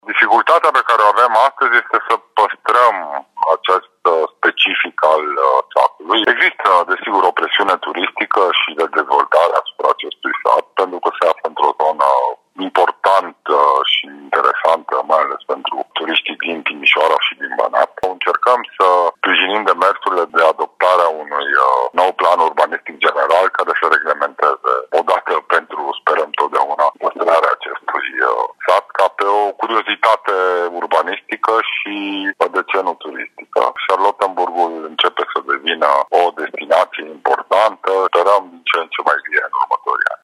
Autoritățile fac acum eforturi să păstreze caracteristicile rurale specifice locului, spune directorul Direcției pentru Cultură Timiș, Sorin Predescu.